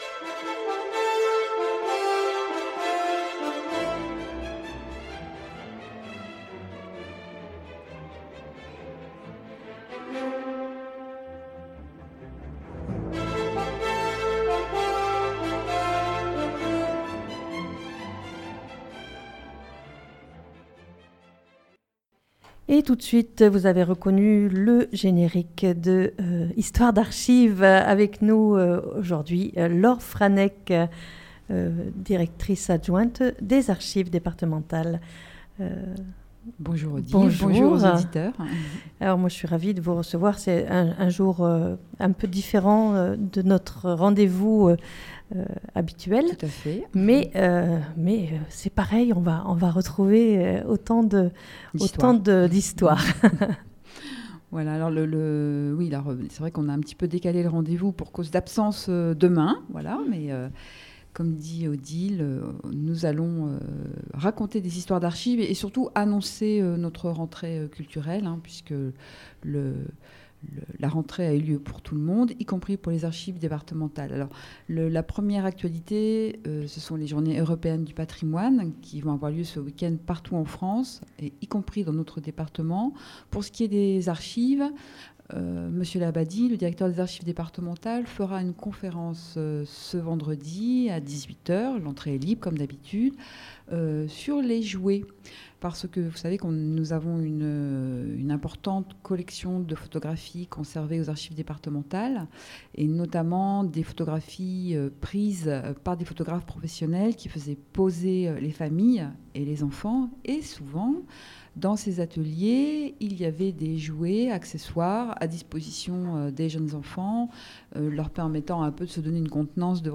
Une émission mensuelle chaque 3ème jeudi du mois de 9h15 à 10h